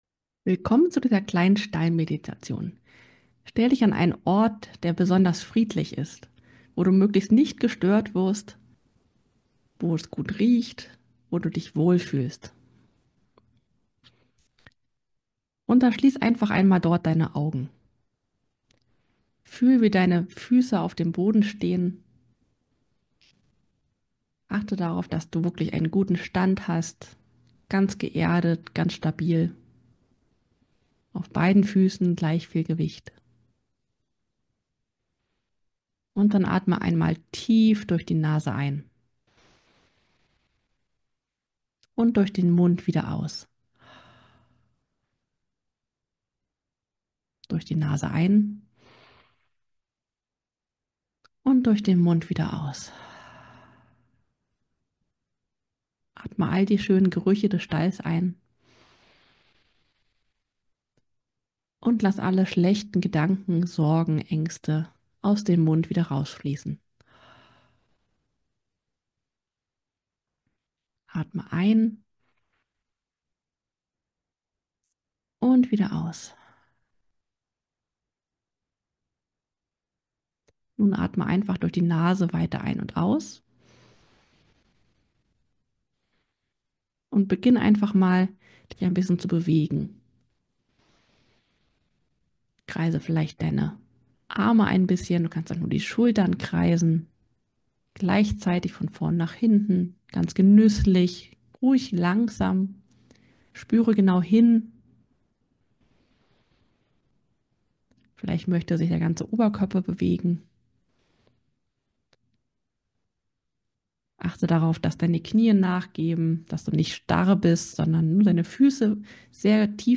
Kleine Atem-Meditation um ganz bei deinem Pferd anzukommen, einfach im Stehen (ca. 5 Min.)